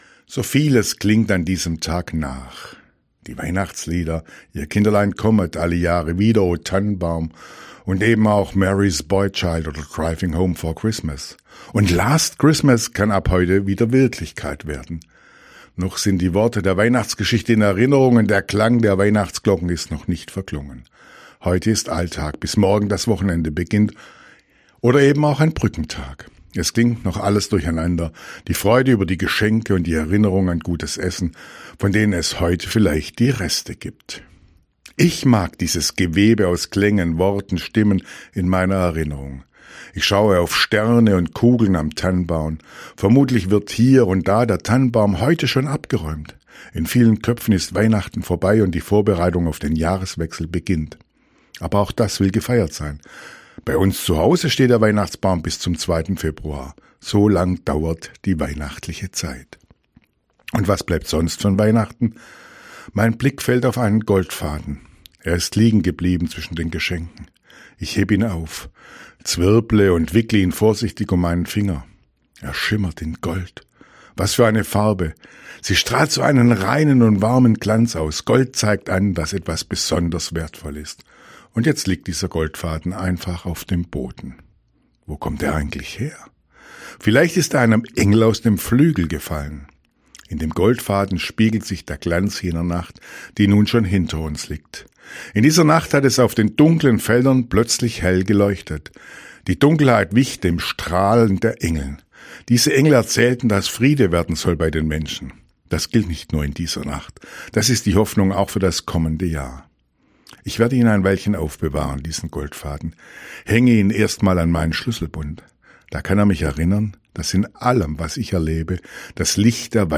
Radioandacht vom 27. Dezember